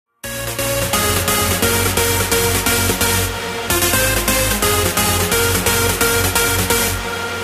• Качество: 128, Stereo
громкие
Electronic
электронная музыка
без слов
Trance